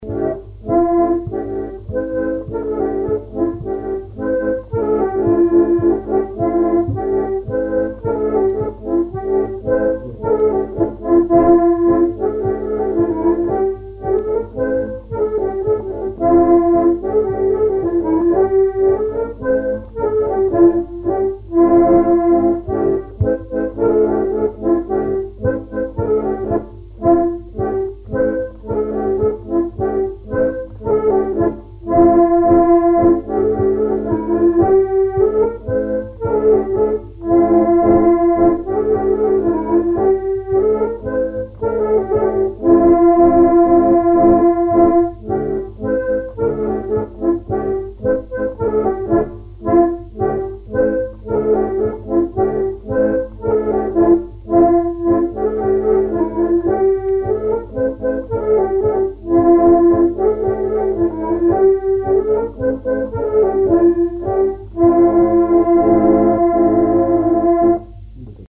Mémoires et Patrimoines vivants - RaddO est une base de données d'archives iconographiques et sonores.
danse : polka piquée
Pièce musicale inédite